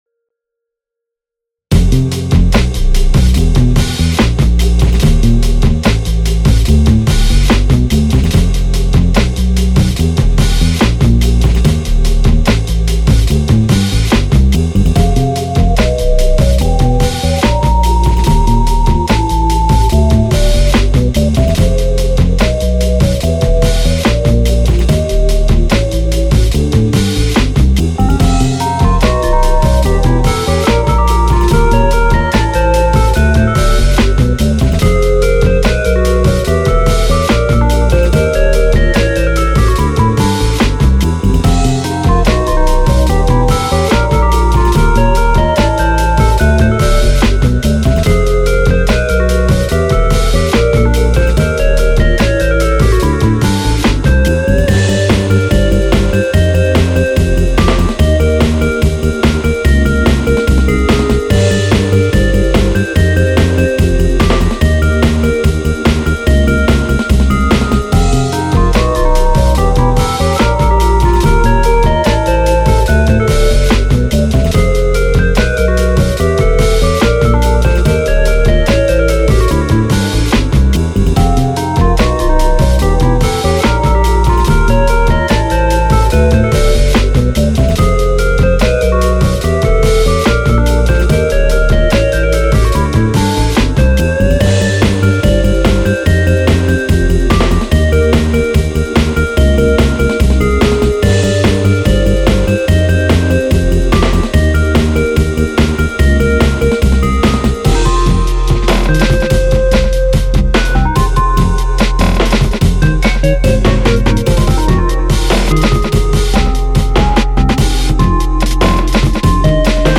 Grooooooooveey